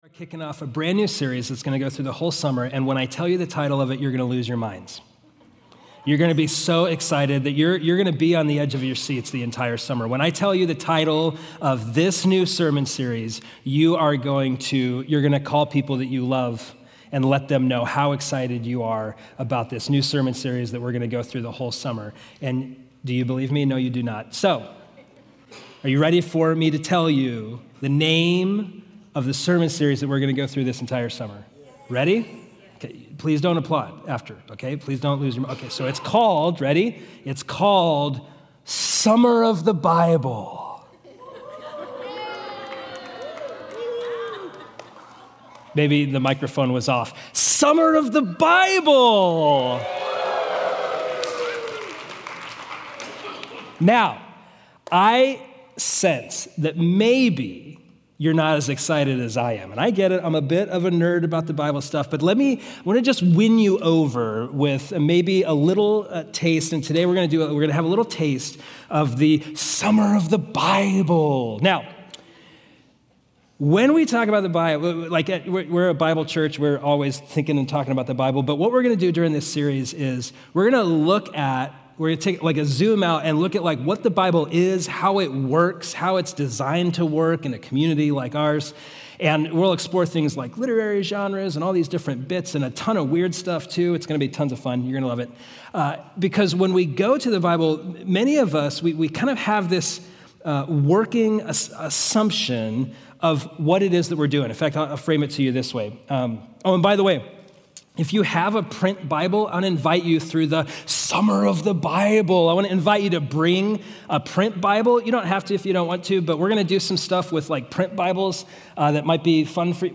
The sermon also emphasizes that the Bible was historically encountered communally, often heard aloud rather than privately read. Through a campfire illustration and a shared reading of Genesis 1:1–5, the congregation practices imagining the text together, demonstrating how shared engagement broadens understanding and guards against distortion.